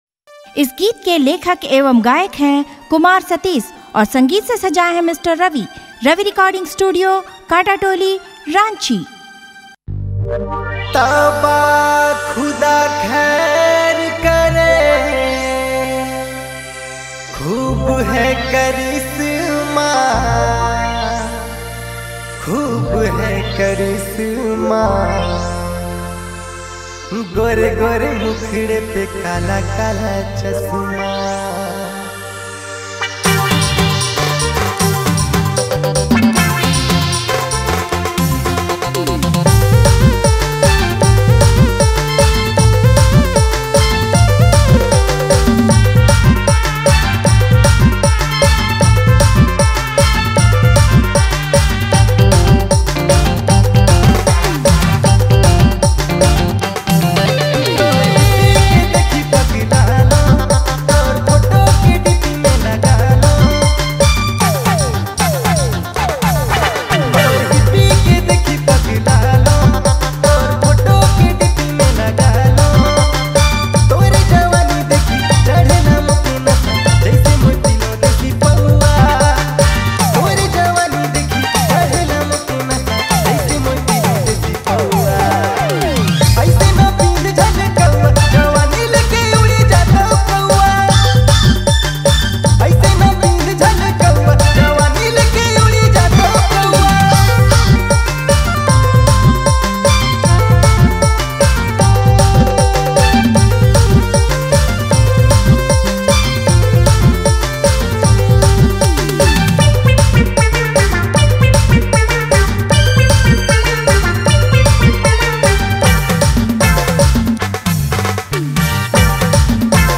Dj Remixer
New Latest Nagpuri Song